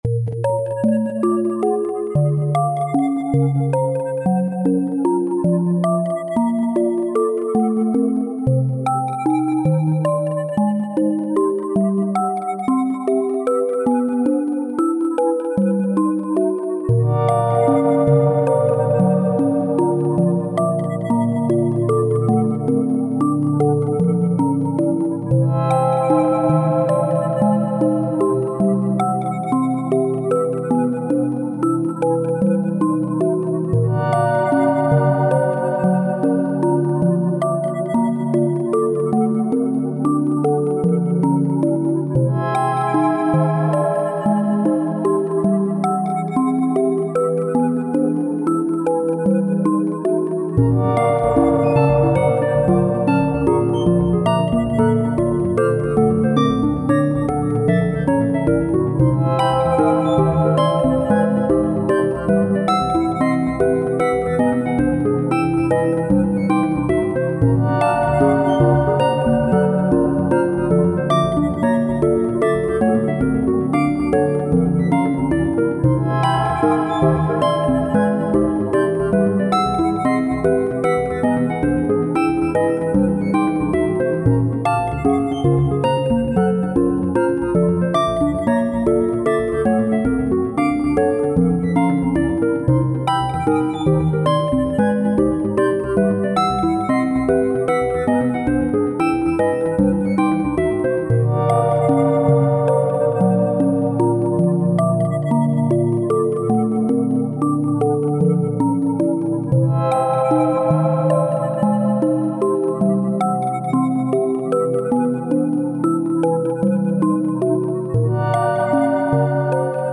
ホールトーンスケールを使って少しネガティブで不思議な雰囲気にまとめている。